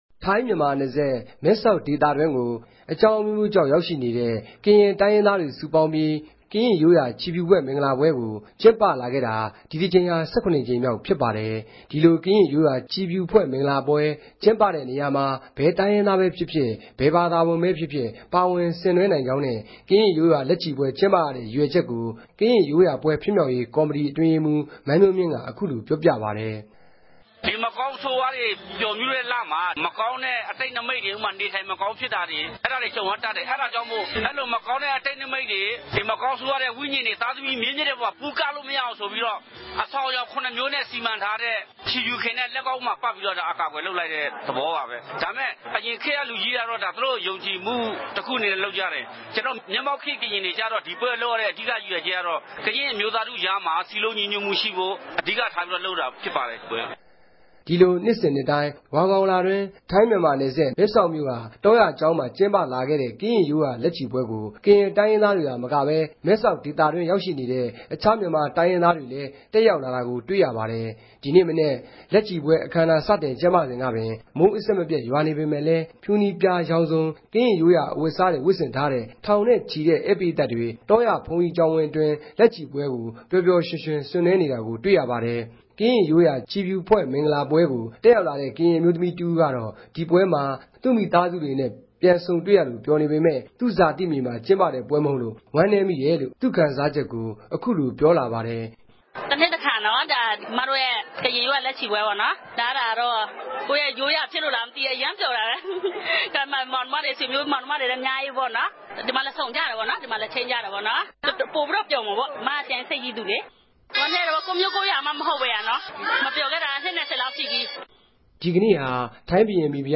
သတင်းပေးပိုႛခဵက်။်